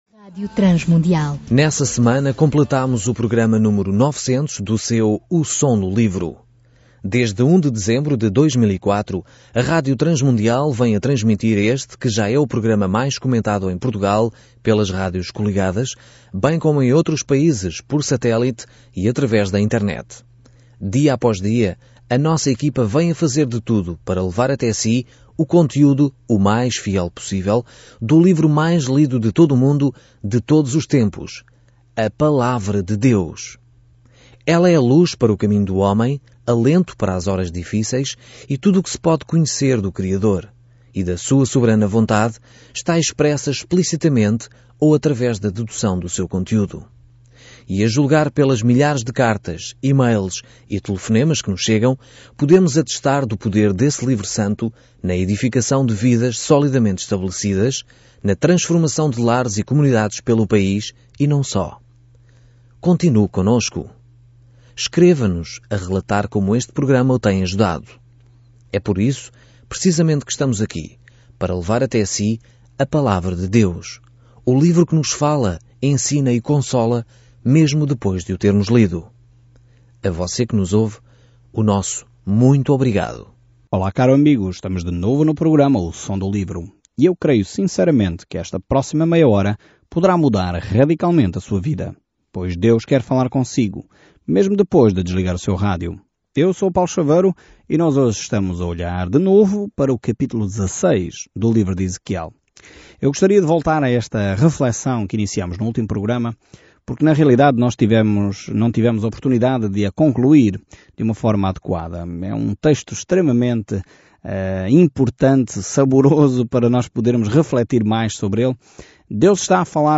Escritura EZEQUIEL 16 EZEQUIEL 17 EZEQUIEL 18 Dia 10 Iniciar este Plano Dia 12 Sobre este plano O povo não quis ouvir as palavras de advertência de Ezequiel para retornar a Deus, então, em vez disso, ele encenou as parábolas apocalípticas, e isso perfurou o coração das pessoas. Viaje diariamente por Ezequiel enquanto ouve o estudo em áudio e lê versículos selecionados da palavra de Deus.